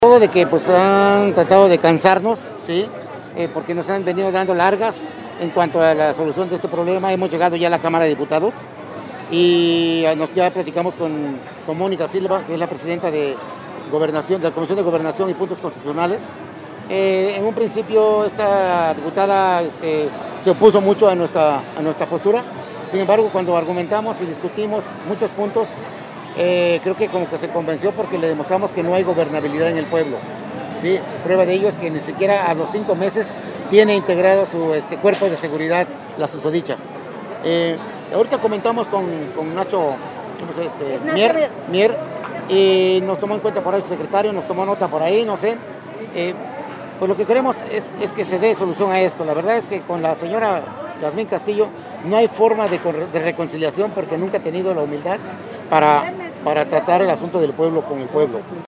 En entrevista los pobladores, señalaron que se enteraron de la reunión que se iba a realizar en Acatlán, y que por eso acudieron con la esperanza de que el diputado federal intervenga.